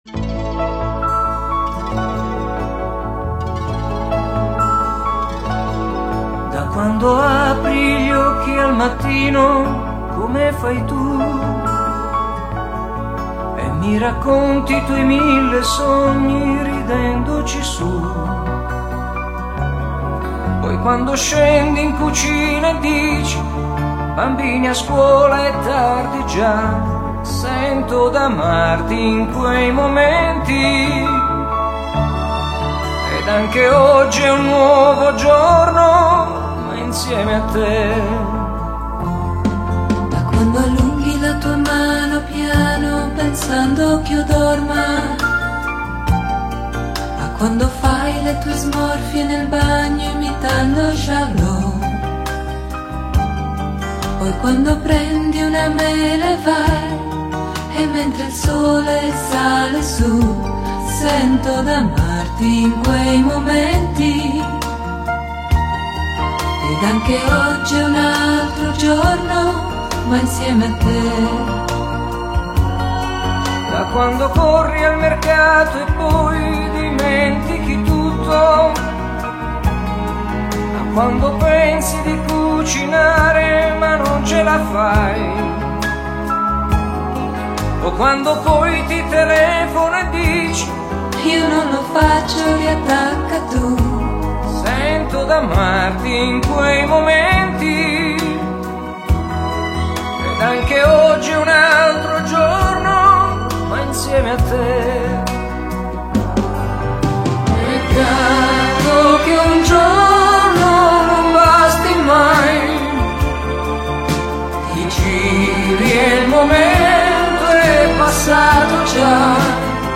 Pop Italiano, Disco Pop